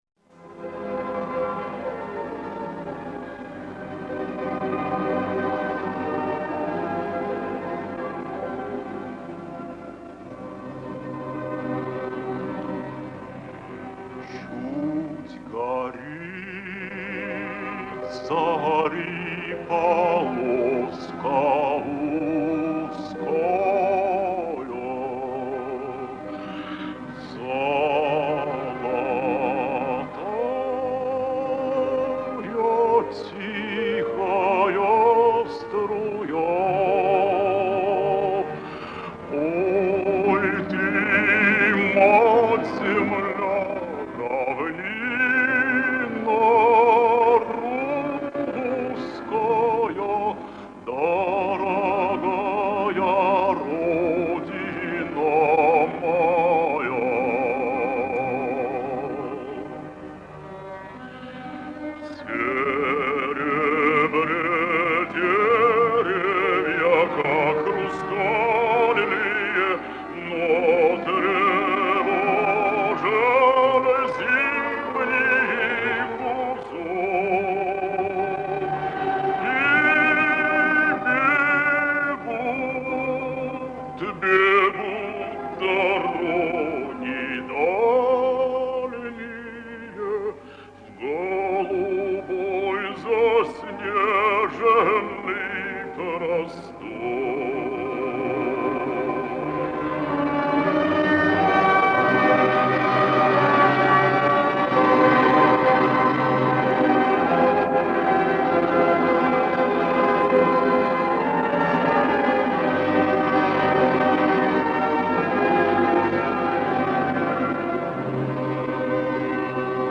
Запись с магнитофонной ленты